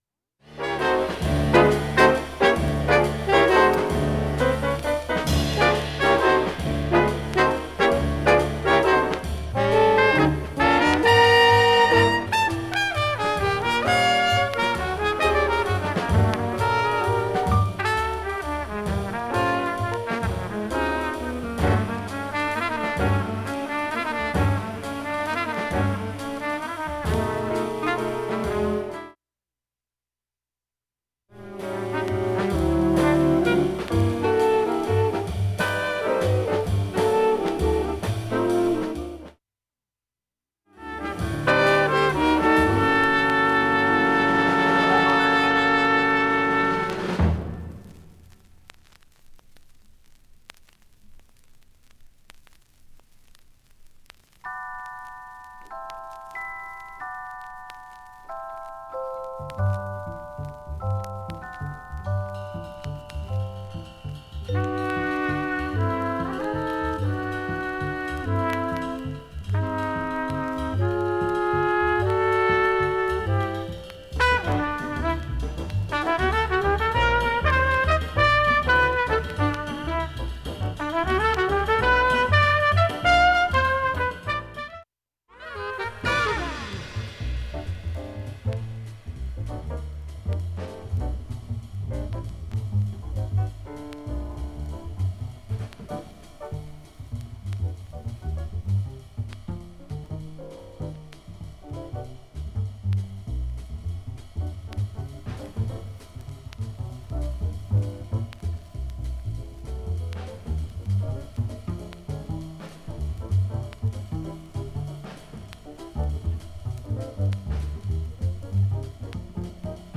それ以外きれいで音質良好全曲試聴済み。
B-2終盤かなり静かな部で
５０秒間かすかなプツが出ています。
◆ＵＳＡ盤オリジナル Mono